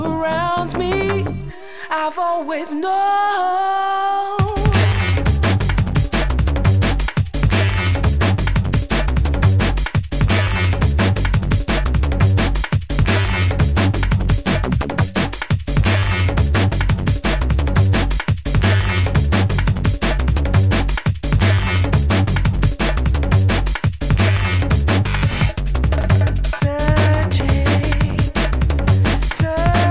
TOP > Deep / Liquid